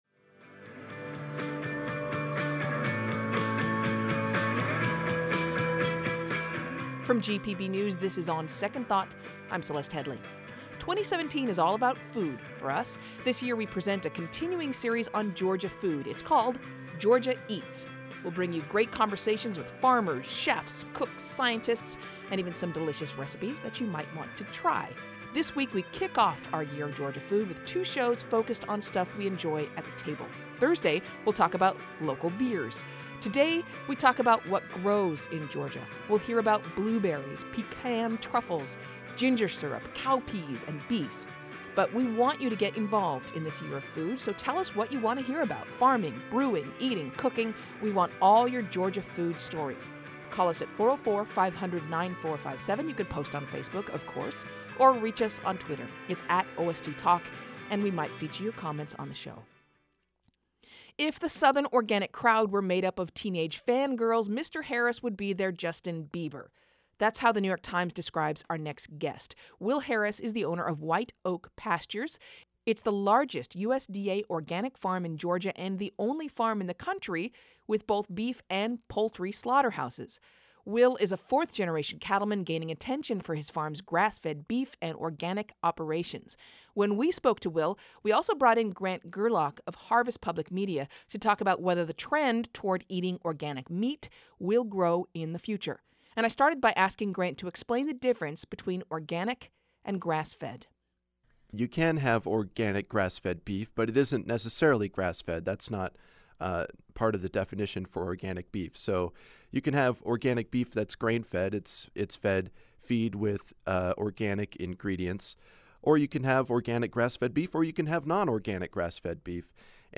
This year we present a continuing series called “Georgia Eats.” We’ll bring you great conversations with farmers, chefs, cooks, scientists and even some delicious recipes you may want to try.